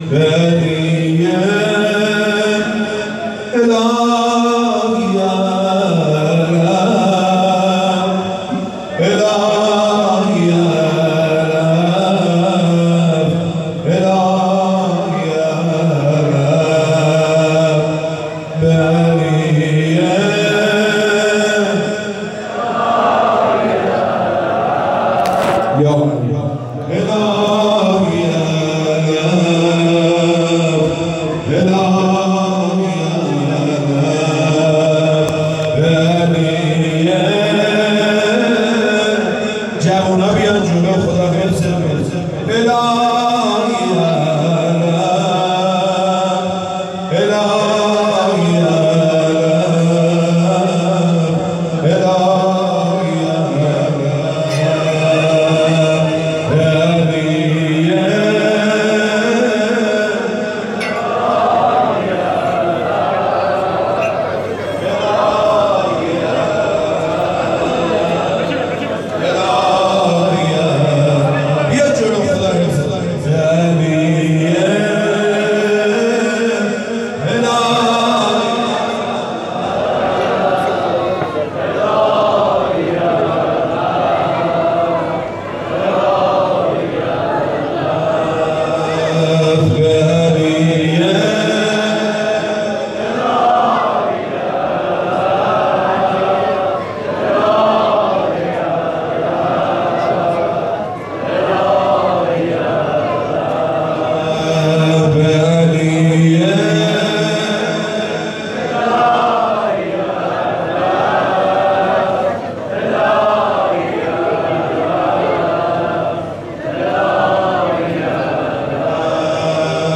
شب اول قدر (19رمضان) 1440
2-دم-ورود-به-واحد.mp3